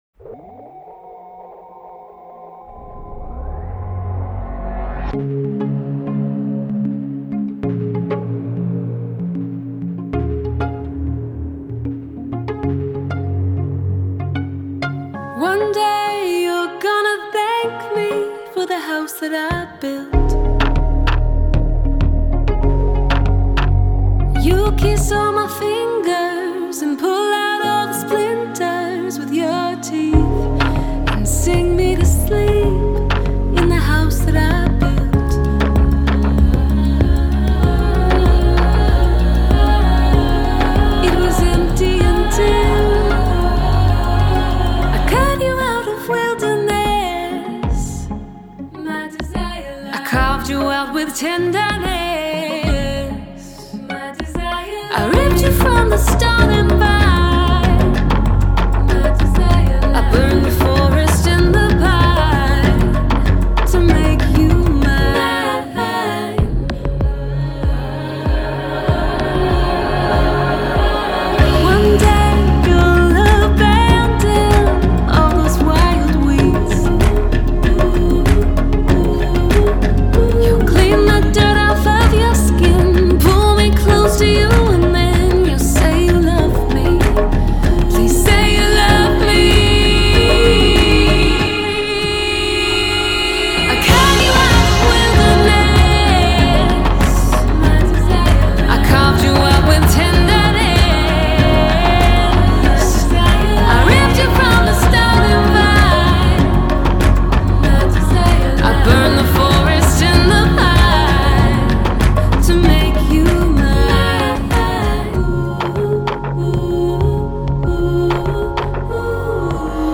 alt-pop EP
lyric-driven, experimental approach to pop